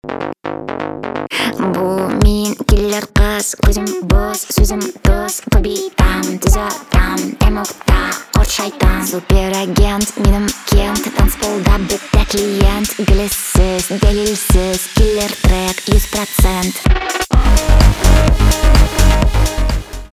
инди
битовые , басы
качающие